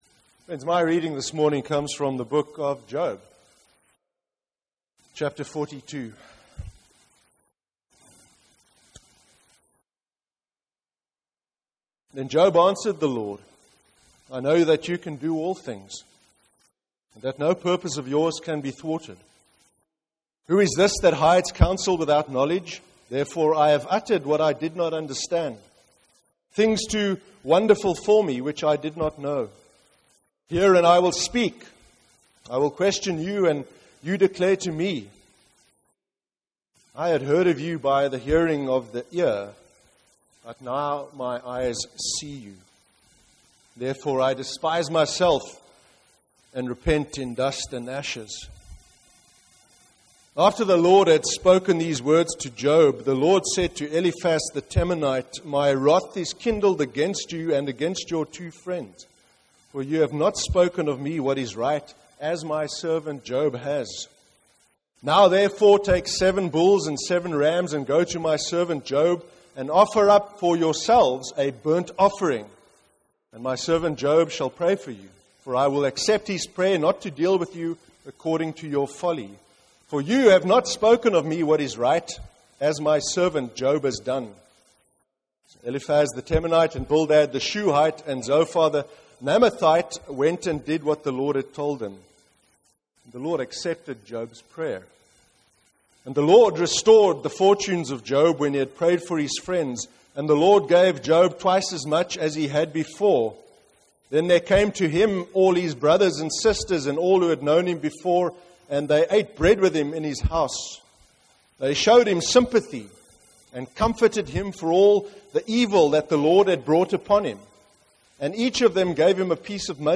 05/04/2015 sermon – Easter Sunday. Resurrection (Job 42)